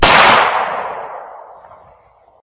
gun2.ogg